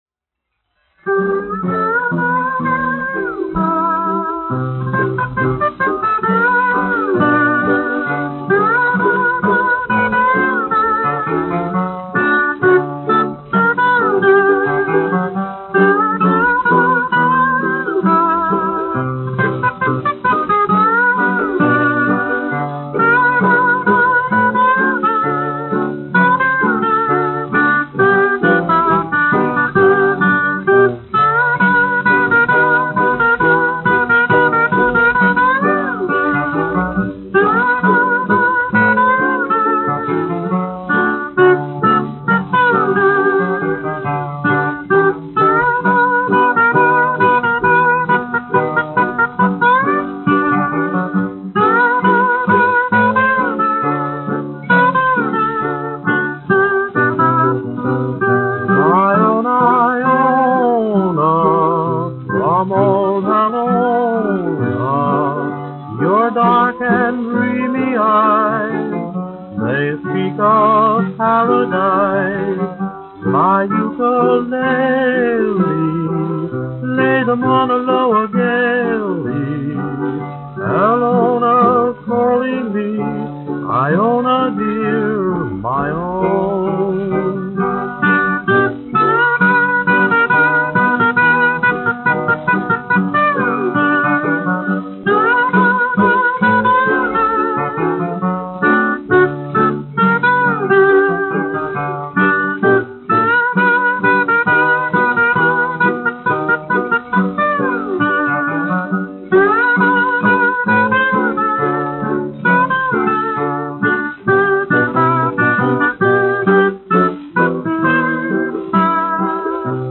1 skpl. : analogs, 78 apgr/min, mono ; 25 cm
Populārā mūzika
Fokstroti